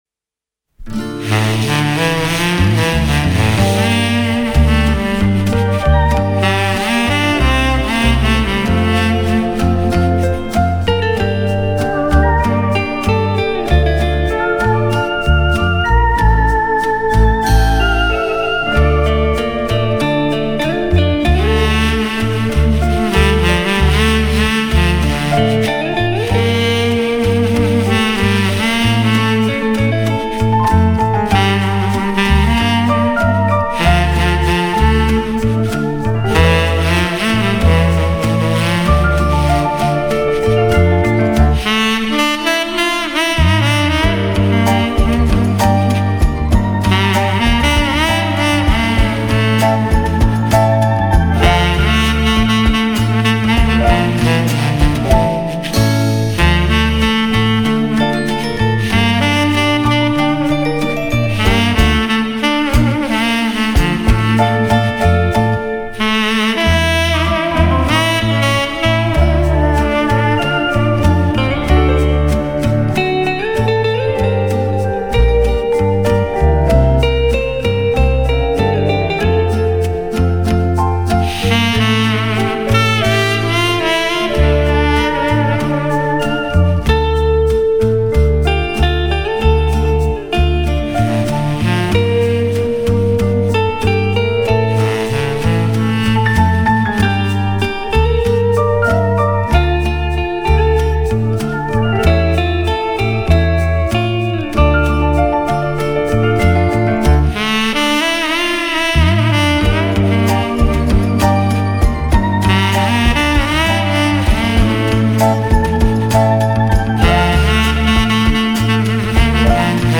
Подборка треков с улучшенным мною качеством звука